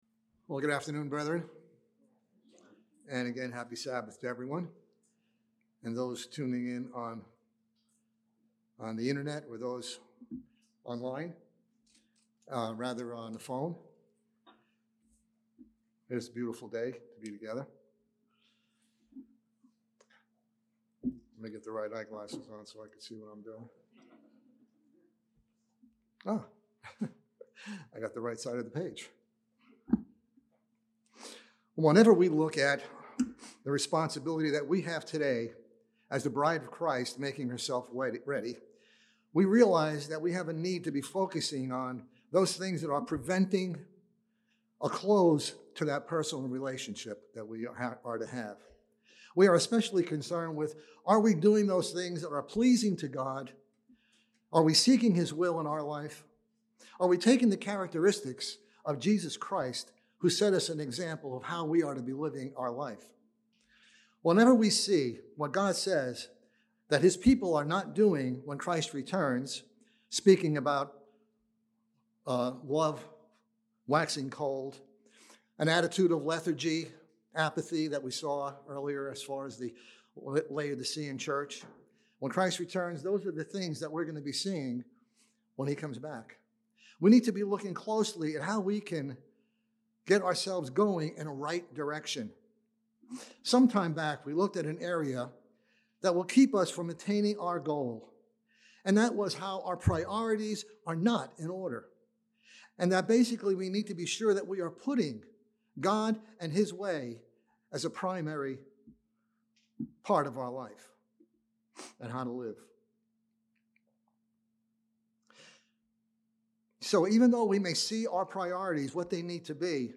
Given in Charlotte, NC Columbia, SC Hickory, NC